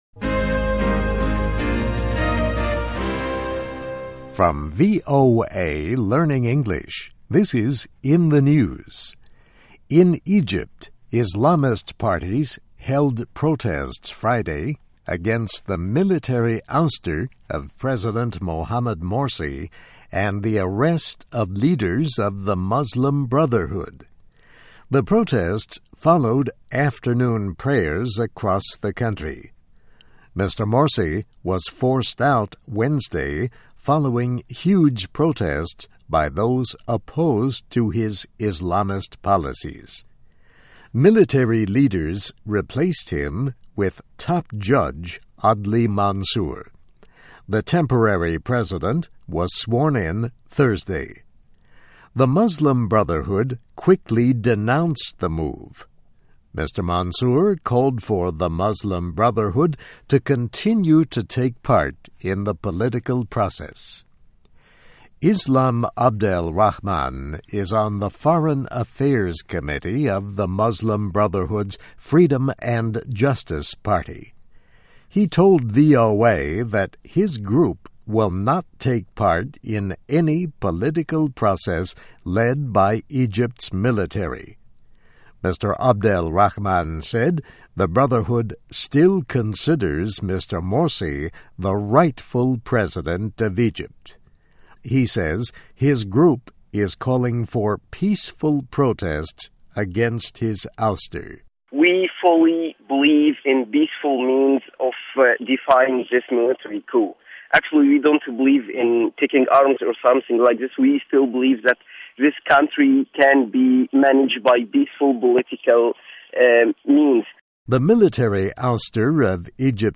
VOA时事新闻-埃及抗议军方废黜民选总统|VOA慢速英语
Welcome to VOA Special English.If you find this programme easy,we suggest you go to VOA Standard English.Enjoy your stay here!